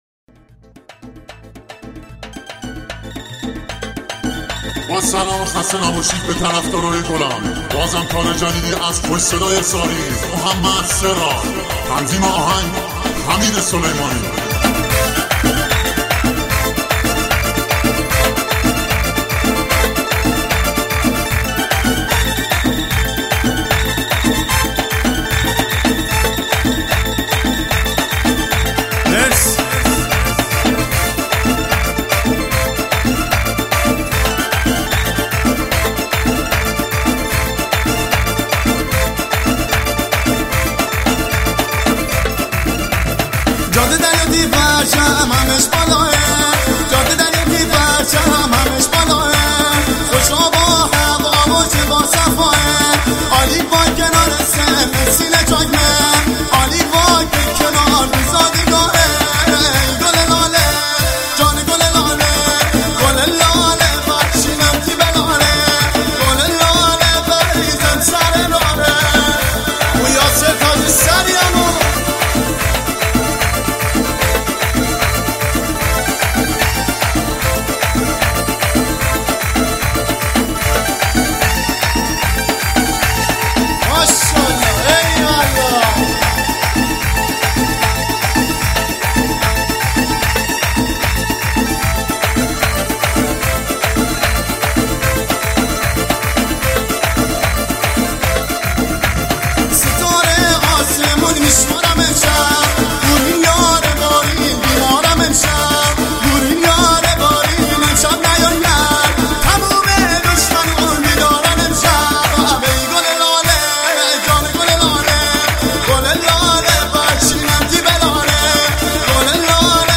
ریمیکس تند بیس دار
آهنگ شاد مازندرانی رقصی